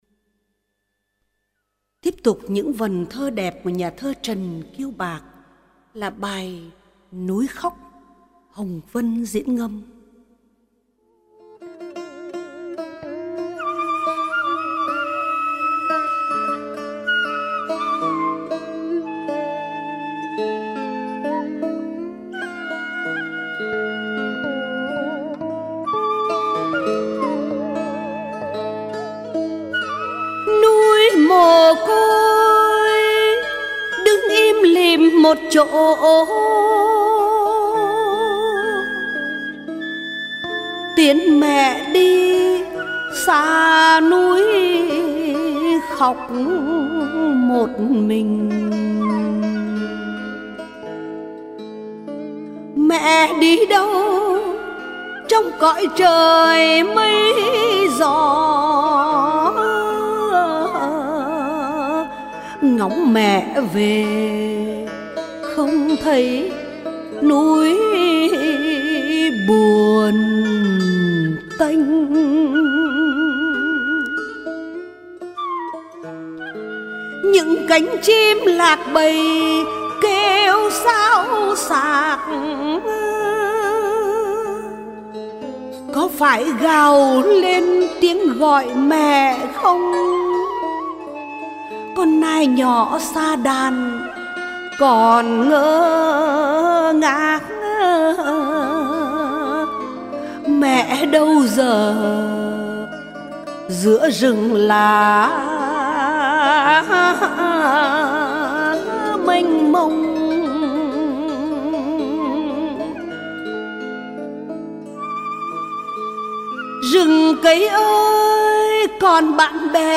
Ngâm Thơ | Sáng Tạo